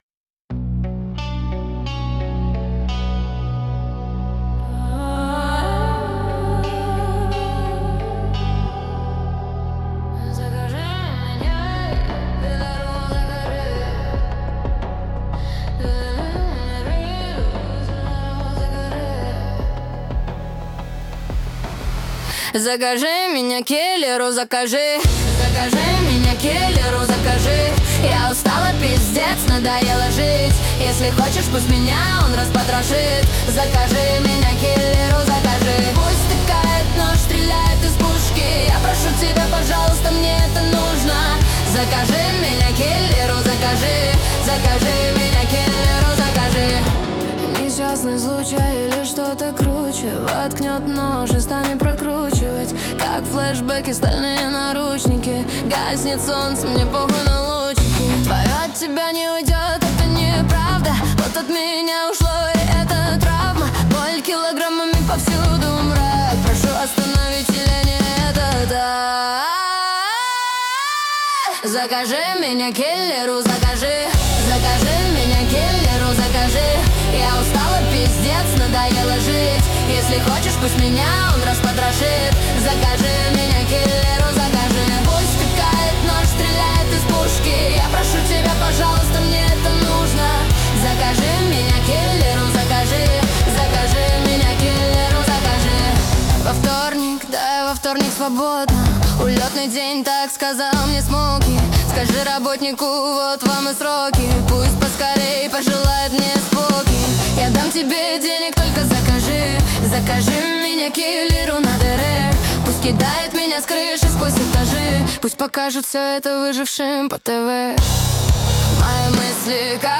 120 BPM
Afrobeat